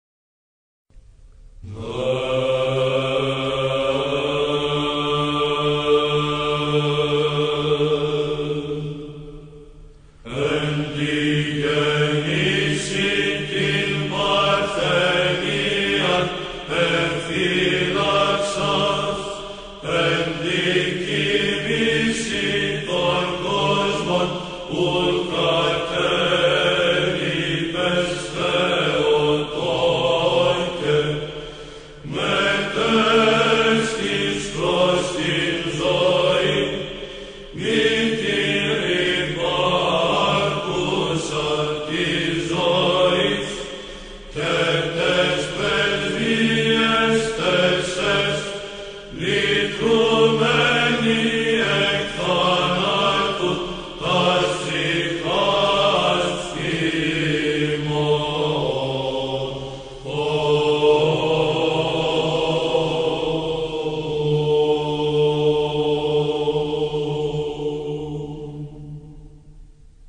Ἀπολυτίκιον. Ἦχος α΄.
ΑΠΟΛΥΤΙΚΙΟΝ-ΚΟΙΜΗΣΕΩΣ-ΘΕΟΤΟΚΟΥ.mp3